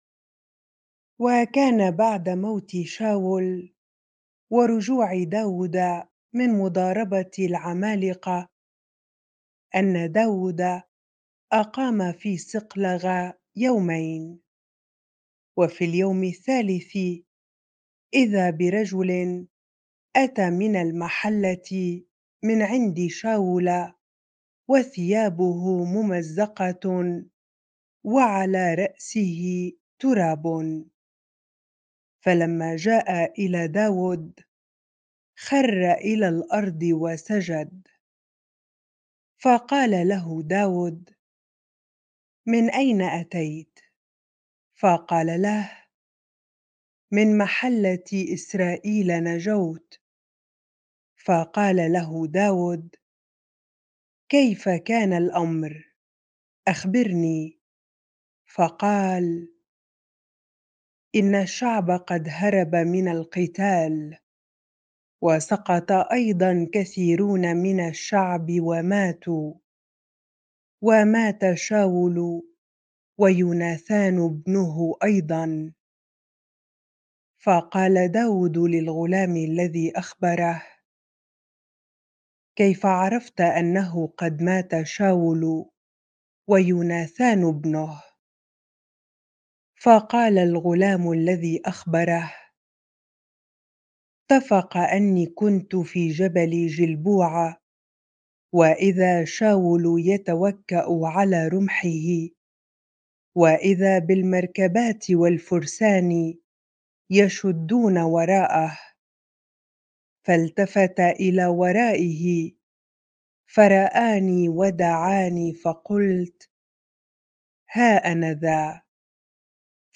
bible-reading-2Samuel 1 ar